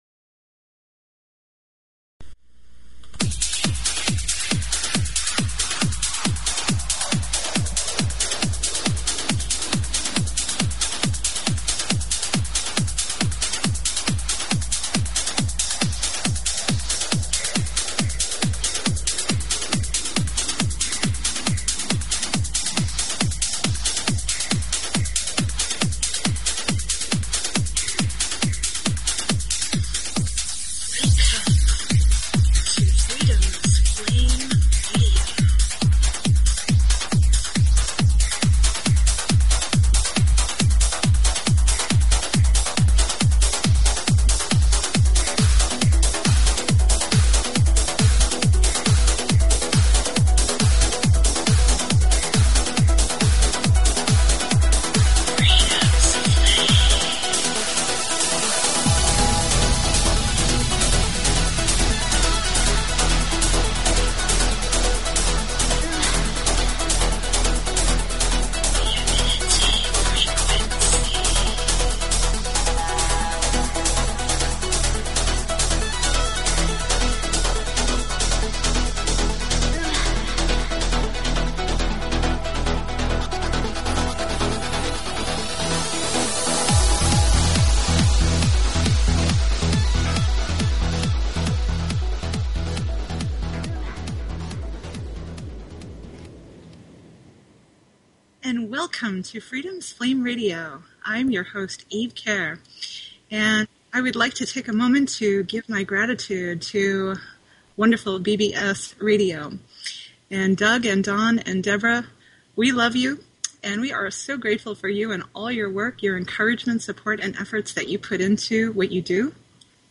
Talk Show Episode, Audio Podcast, Freedoms_Flame_Radio and Courtesy of BBS Radio on , show guests , about , categorized as